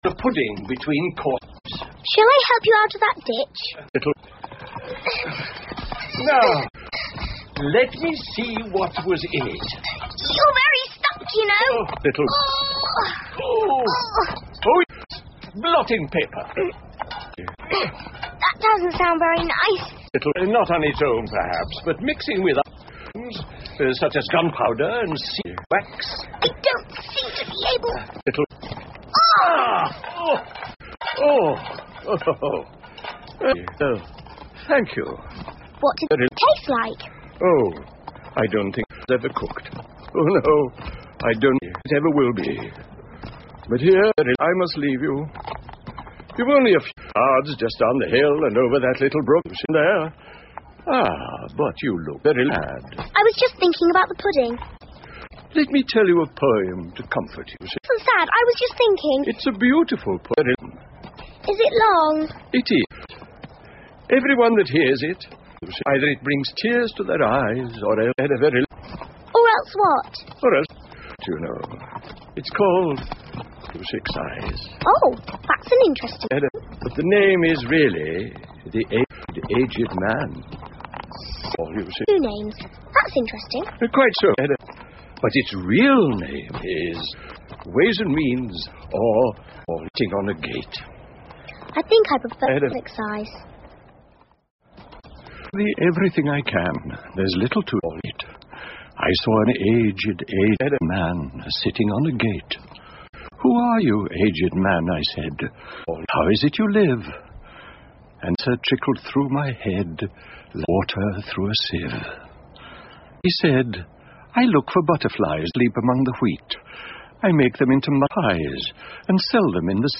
Through The Looking Glas 艾丽丝镜中奇遇记 儿童广播剧 18 听力文件下载—在线英语听力室